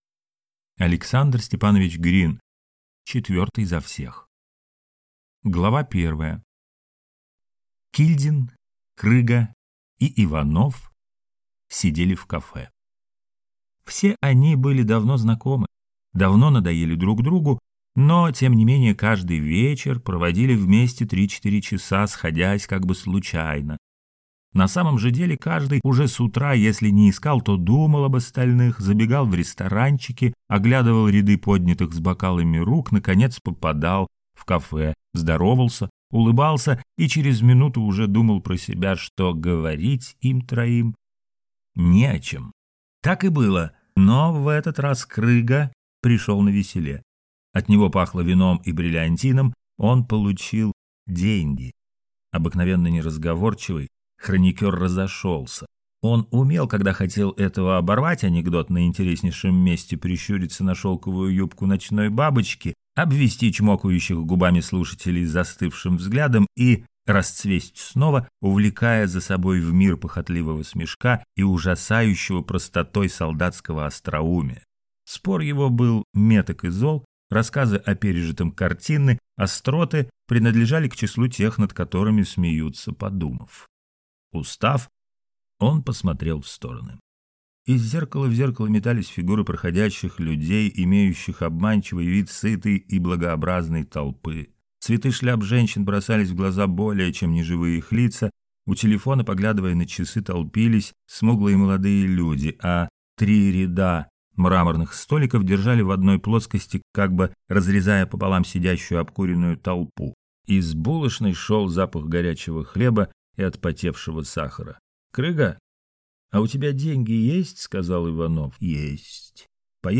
Аудиокнига Четвертый за всех | Библиотека аудиокниг